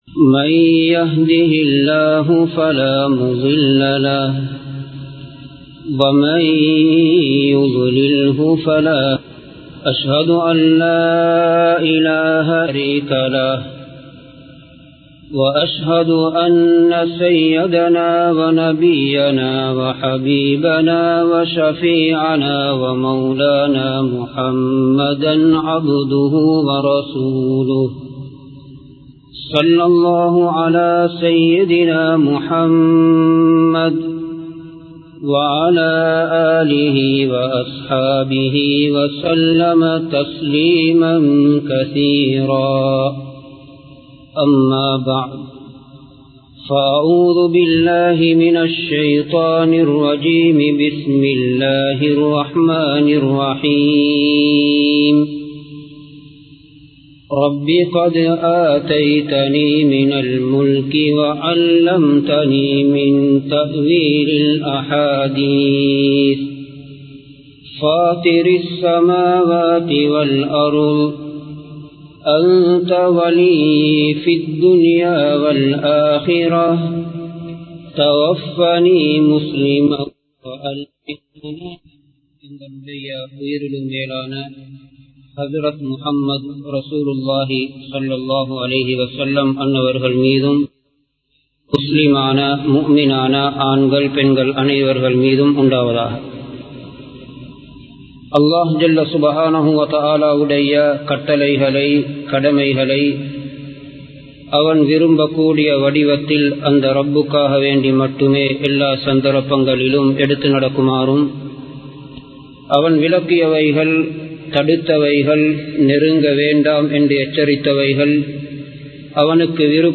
முடிவை அழகாக்குவோம் | Audio Bayans | All Ceylon Muslim Youth Community | Addalaichenai
Kollupitty Jumua Masjith